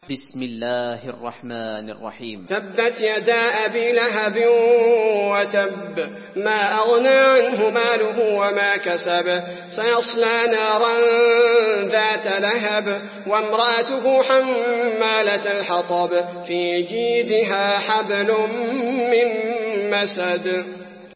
المكان: المسجد النبوي المسد